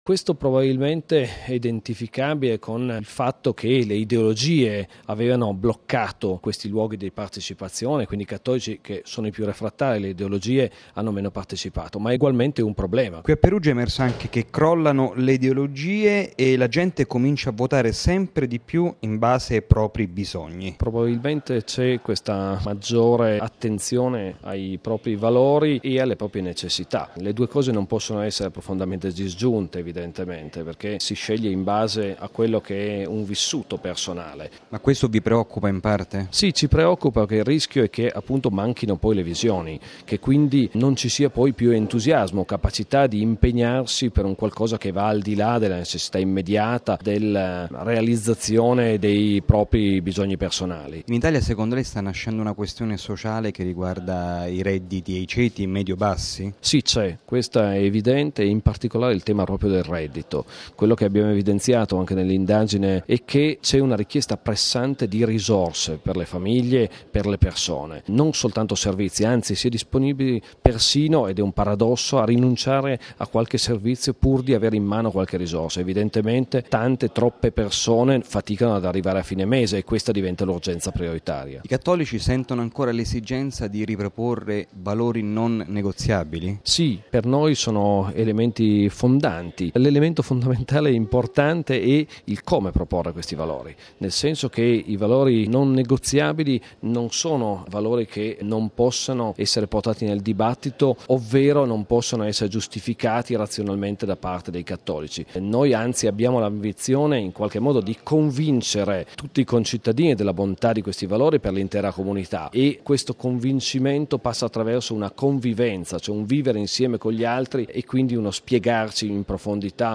Convegno ACLI: intervista